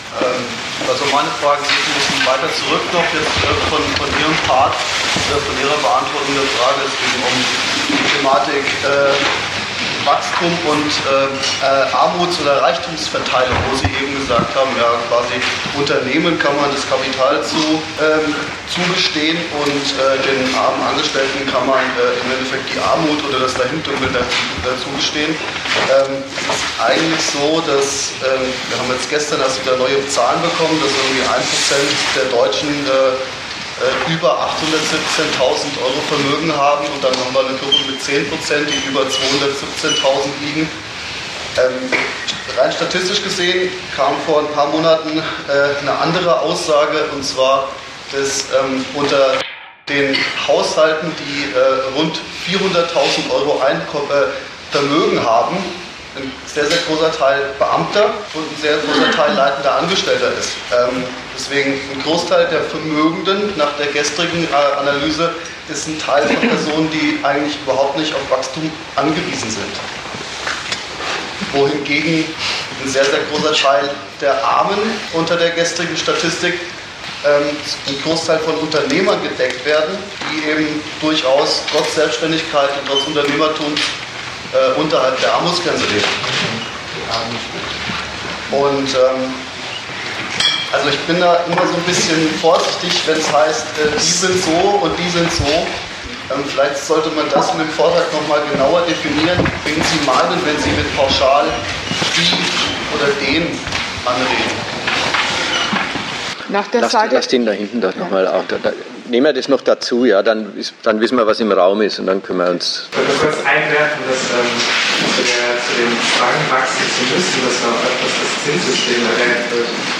Datum 27.02.2014 Ort Nürnberg Themenbereich Arbeit, Kapital und Sozialstaat Veranstalter Sozialistische Gruppe Dozent Gastreferenten der Zeitschrift GegenStandpunkt Wahnsinn Wachstum ...